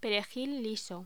Locución: Perejil liso
voz